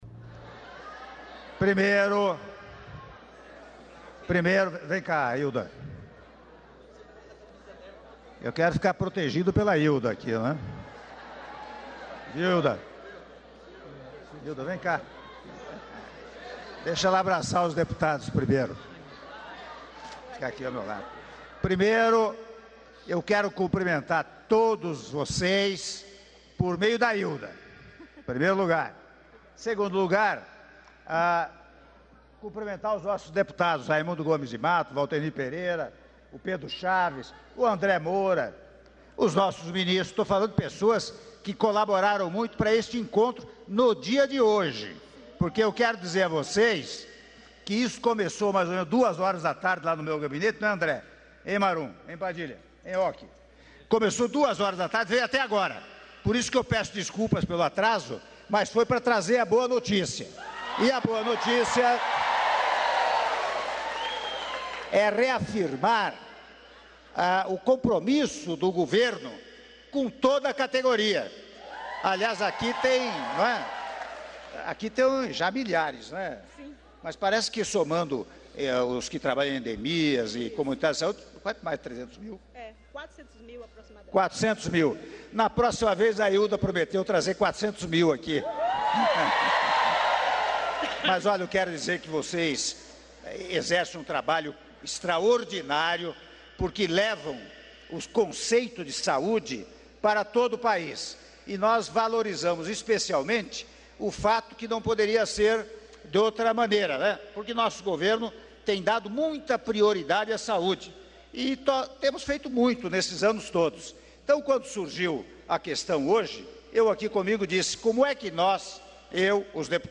Áudio do discurso do Presidente da República, Michel Temer, durante Encontro com Agentes Comunitários de Saúde - Brasília/DF (03min54s)